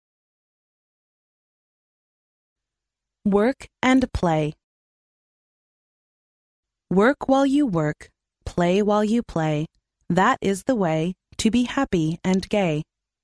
幼儿英语童谣朗读 第56期:工作和娱乐 听力文件下载—在线英语听力室